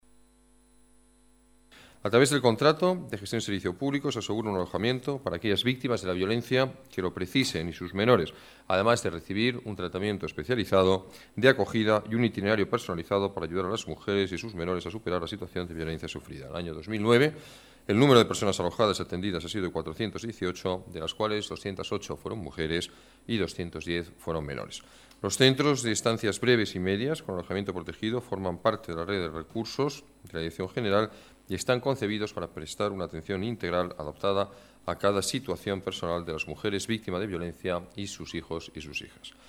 Nueva ventana:Declaraciones del alcalde, Alberto Ruiz-Gallardón: Un 'hogar' para las víctimas de la violencia de género